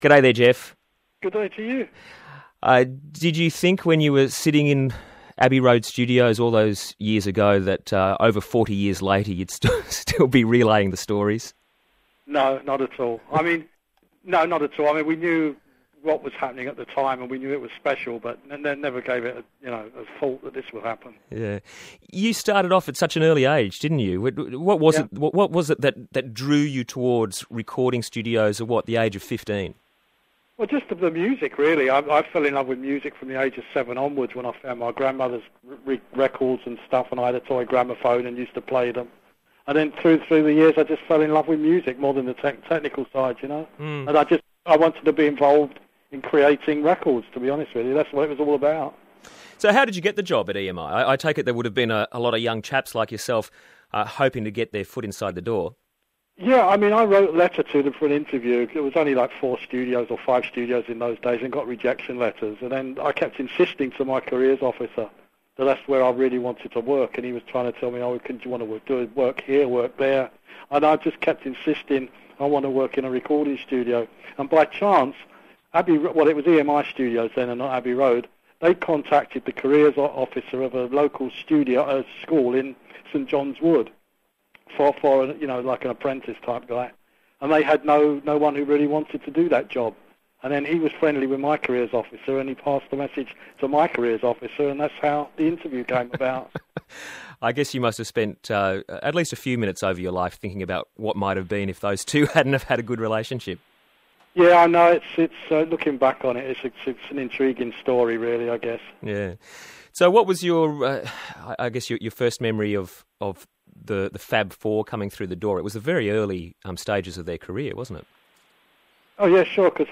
Beatles Engineer Geoff Emerick Interview